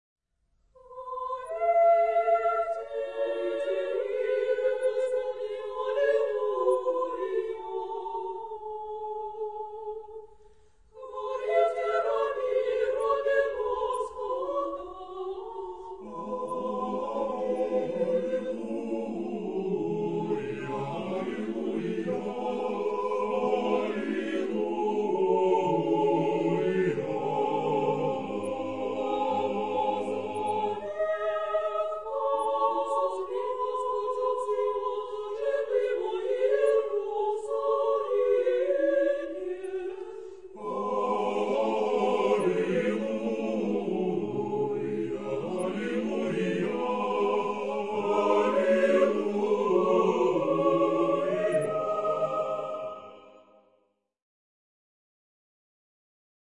SSAATTBB (6 voices mixed) ; Choral score with piano for rehearsal only.
Orthodox liturgical hymn.
Genre-Style-Form: Sacred ; Orthodox liturgical hymn ; Orthodox song ; Psalm ; Polyeleion Mood of the piece: festive ; joyous ; prayerful
Tonality: F major